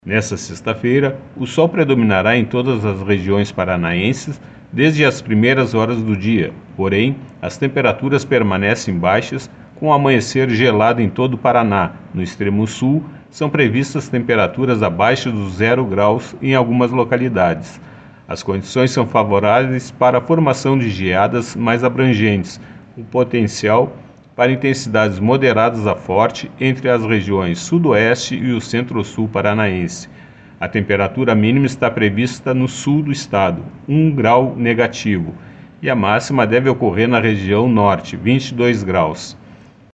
Ouça o que diz o meteorologista do Simepar